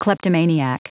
Prononciation du mot kleptomaniac en anglais (fichier audio)
kleptomaniac.wav